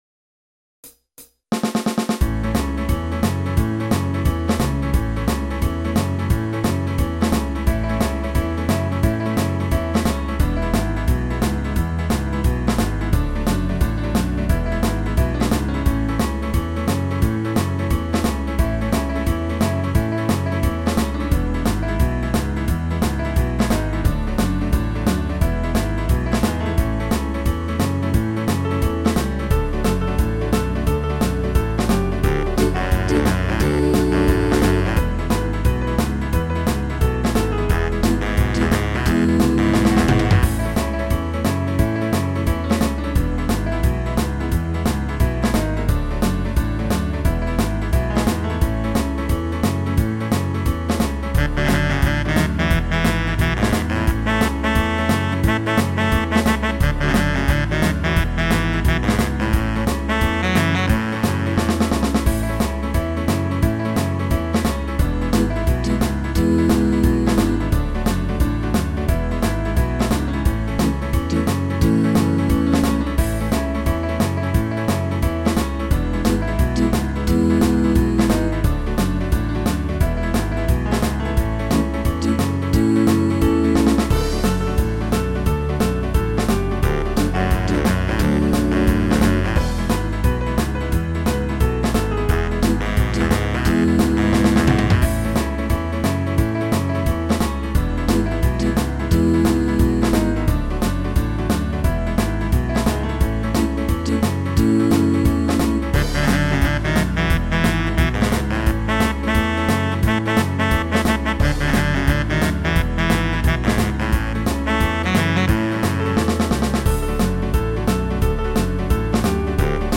4/  Rock and roll
CD známe melódie upravené pre hru na piano